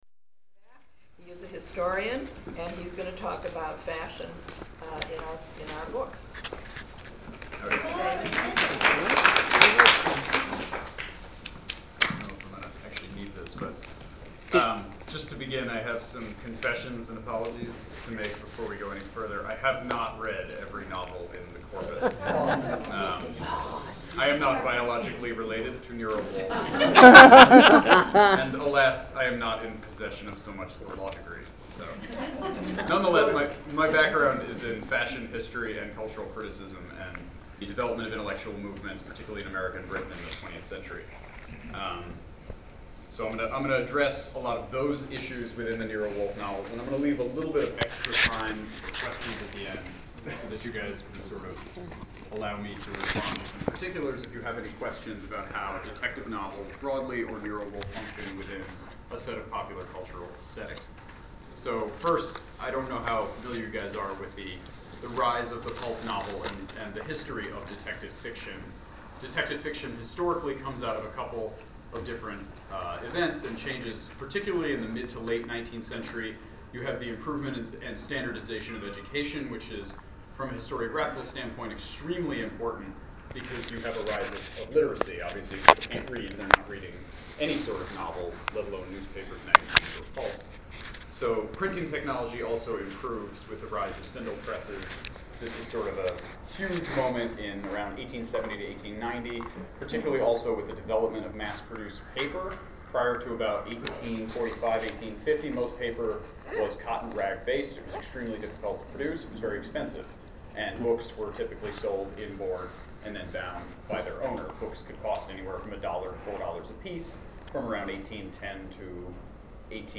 December 3, 2011 — Black Orchid Assembly2:00 PM to 4:30 PM Vanderbilt Suites in the MetLife Building Don't Miss the Slide Show Below